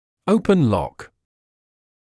[‘əupən lɔk][‘оупэн лок]заклинивание нижней челюсти в открытом положении